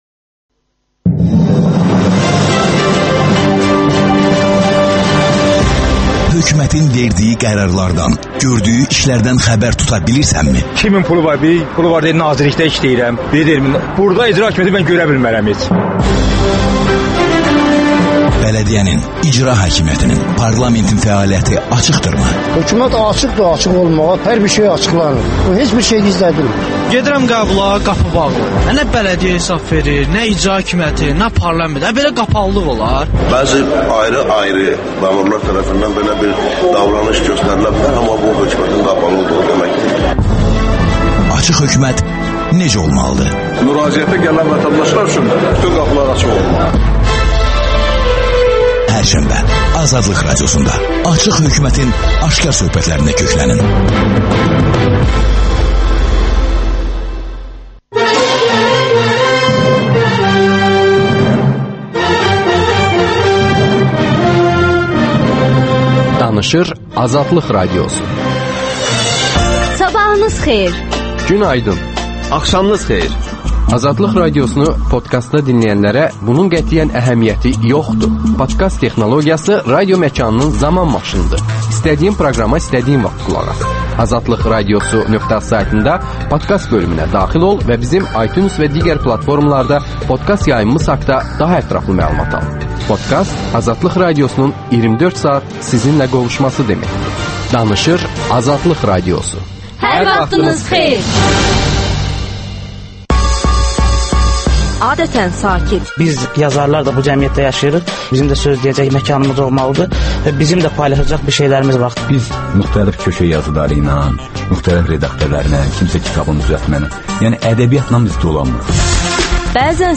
Milli dövləti yıxan satqın, yoxsa Bakıda ən uca heykələ layiq rəhbər? (Debat)